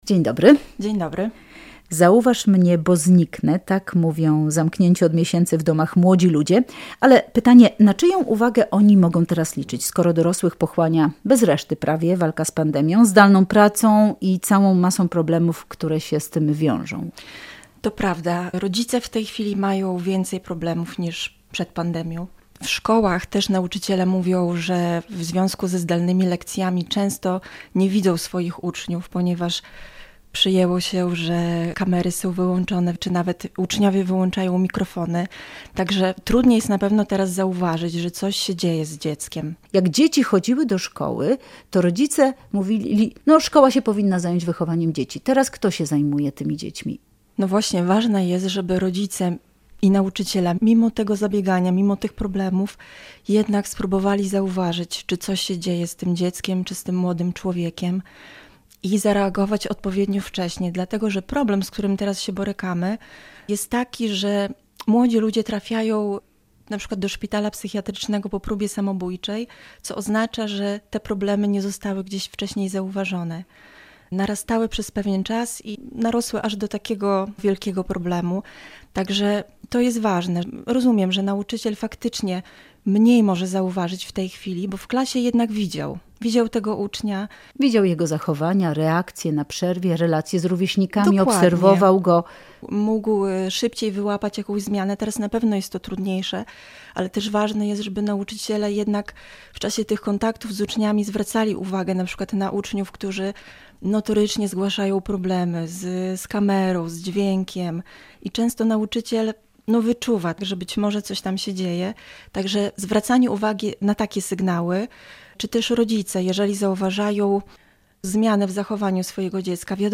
psychoterapeutka